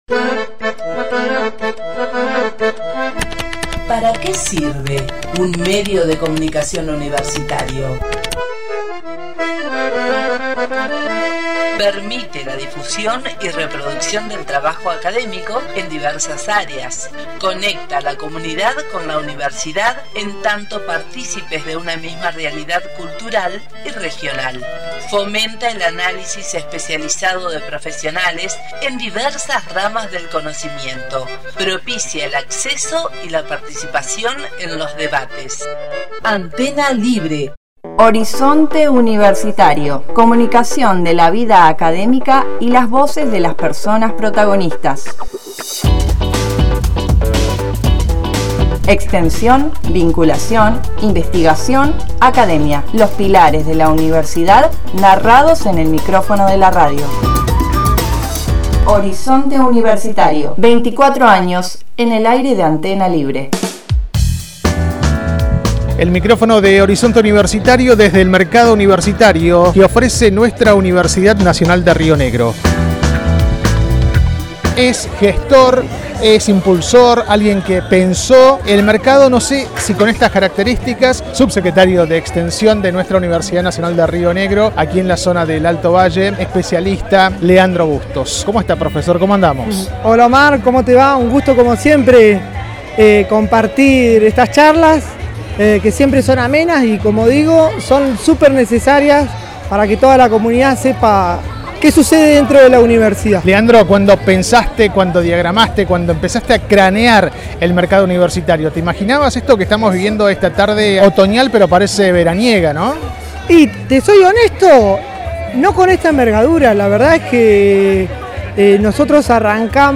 El viernes pasado (10 de abril de 2026), el micrófono de Horizonte Universitario se trasladó al corazón del «Mercado Universitario» organizado por la Universidad Nacional de Río Negro (UNRN) en el Alto Valle. Durante una jornada que congregó a vecinos y estudiantes, la audición registró las voces de las y los protagonistas de la economía social y la vida académica.